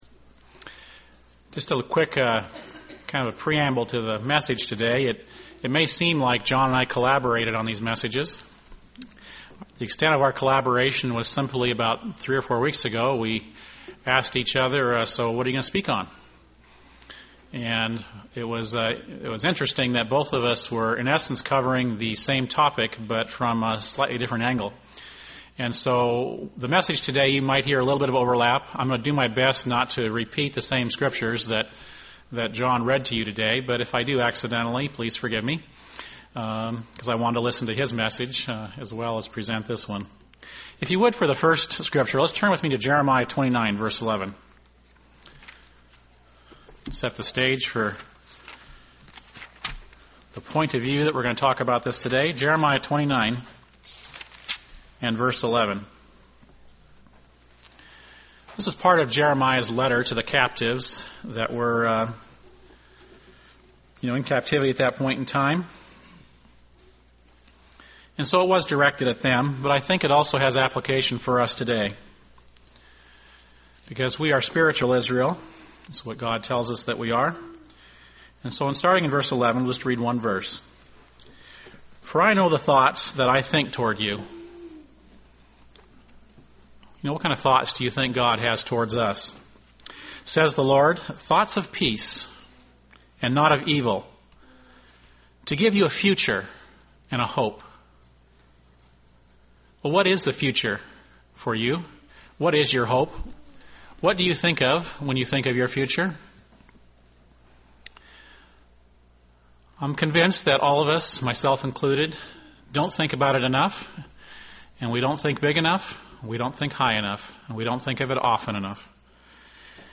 Given in Burlington, WA
UCG Sermon Studying the bible?